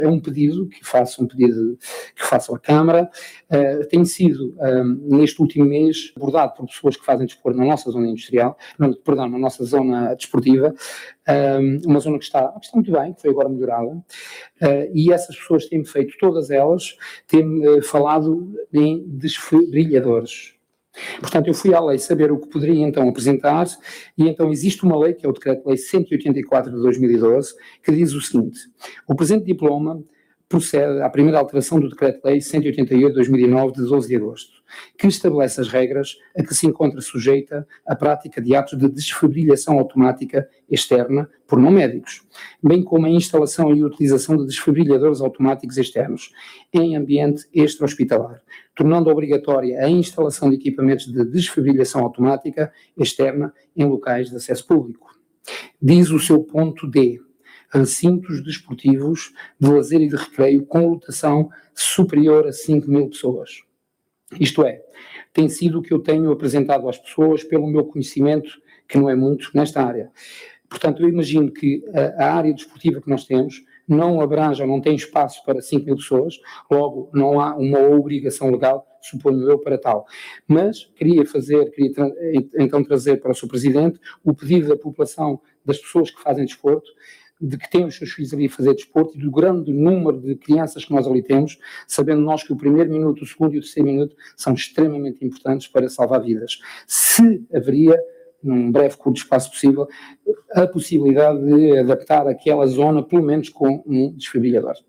Reunião de Câmara do Entroncamento, 16 de novembro de 2021.
“É um pedido que faço à Câmara. Tenho sido abordado por pessoas que fazem desporto na nossa zona desportiva e essas pessoas têm-me falado em desfibrilhadores”, começou por referir o vereador do Chega na sessão do executivo do Entroncamento desta semana.
ÁUDIO | LUIS FORINHO, VEREADOR CHEGA CM ENTRONCAMENTO:
SOM-Luis-Forinho-Desfibrilhador.mp3